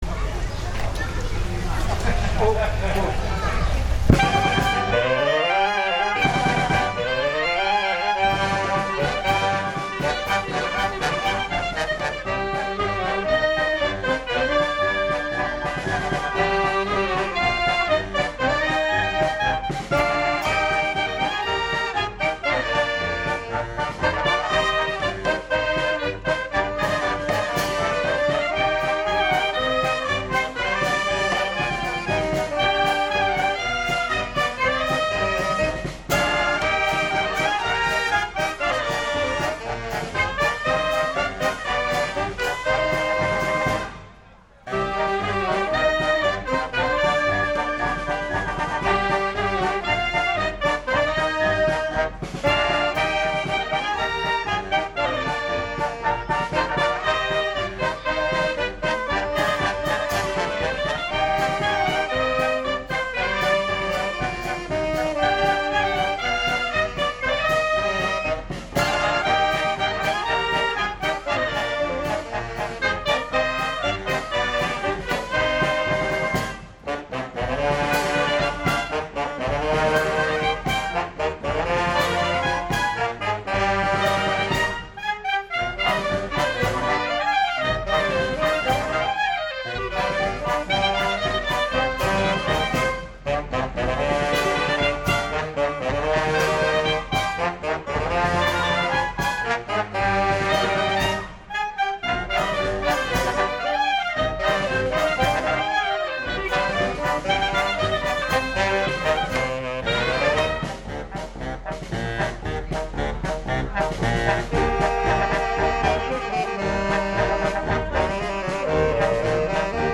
ecco a seguire le quattro marce suonate prima della Messa e della processione che ne è seguita
Il trovarsi in prossimità della banda nella quale suonano anche parenti stretti del vostro cronista però, ha fatto sì che per conflitto d’interesse del quale approfittare, (nonostante mancassero i dispositivi portatili di controllo ed aggiustamento delle audio-registrazioni), si procedesse comunque a registrare alla ‘come vié vié’ ed i risultati sono quelli che si possono ascoltare cliccando gli audio proposti.
Le marce suonate dalle dieci e mezza alle undici, non sono state tre, ma quattro.